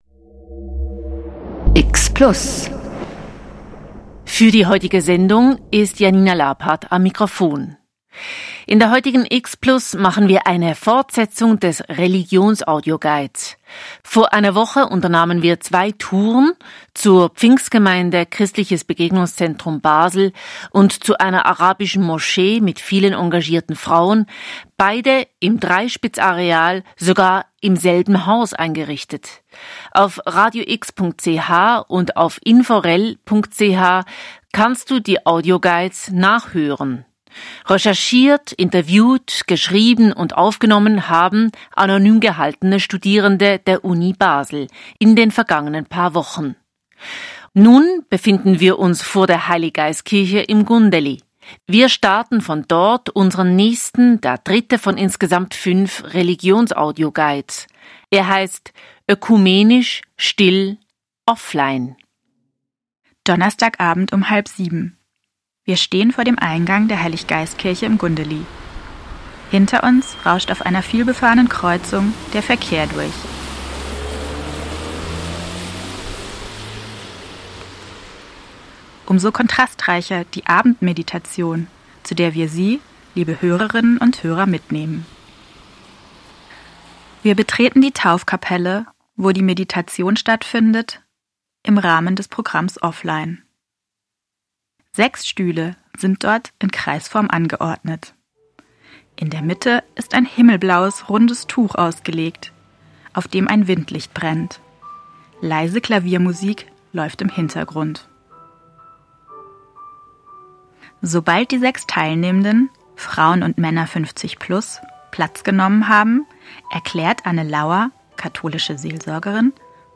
Sie recherchierten, nahmen an Radiocrashkursen teil, interviewten Fachpersonen der entsprechenden Religionsgemeinschaft, schrieben Manuskripte und standen am Mikrofon. Daraus entstanden fünf Religionsaudioguides.